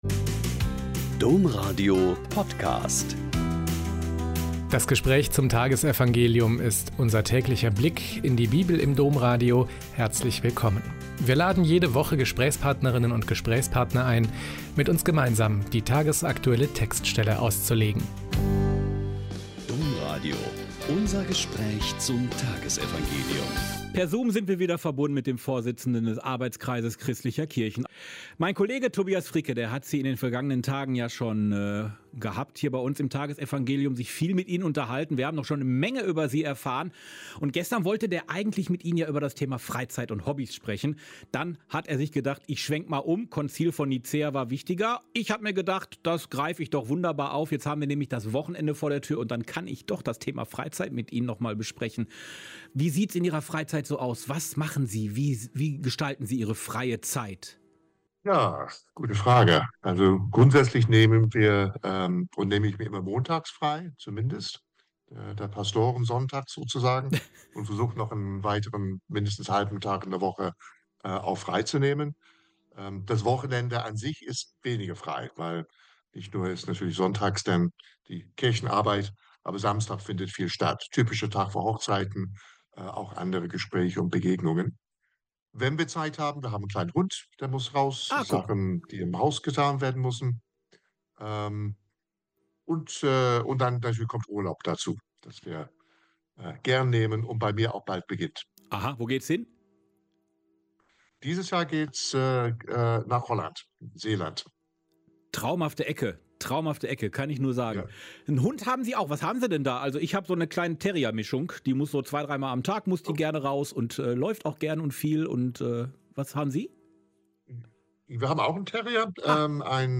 Mt 13,24-30 - Gespräch